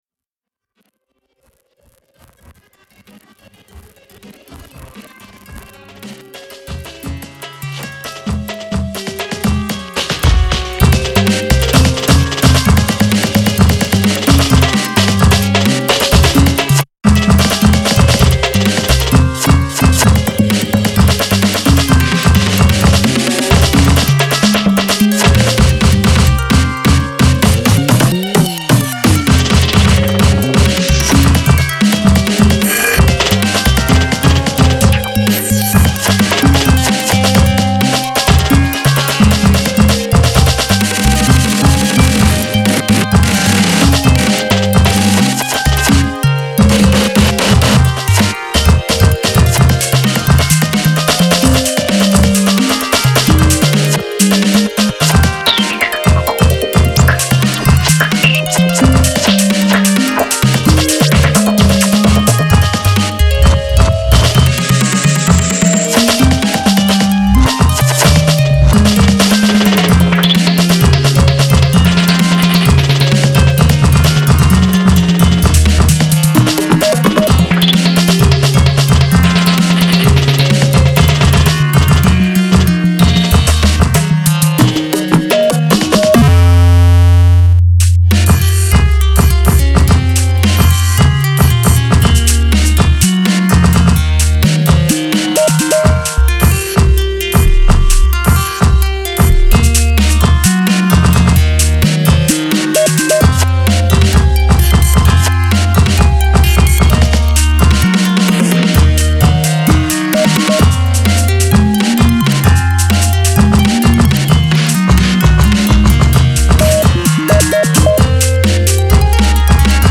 Occidental audio cut-up antics par excellence!